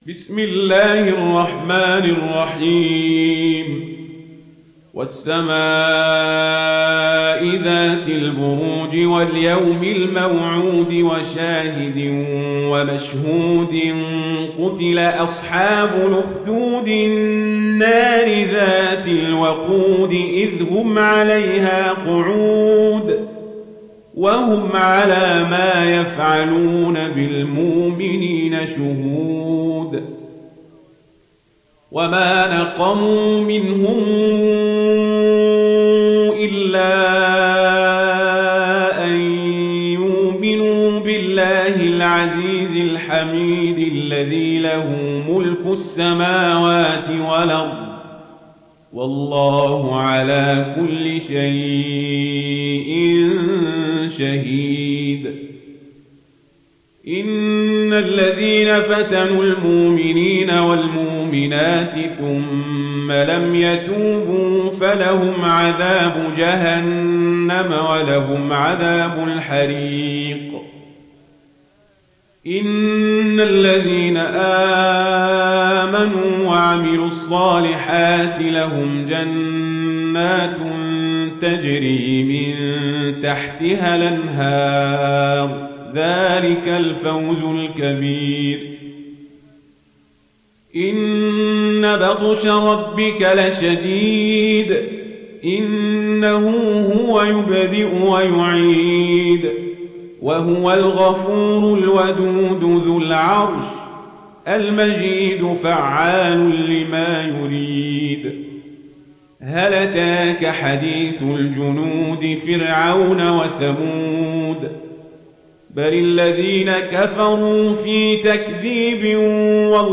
تحميل سورة البروج mp3 بصوت عمر القزابري برواية ورش عن نافع, تحميل استماع القرآن الكريم على الجوال mp3 كاملا بروابط مباشرة وسريعة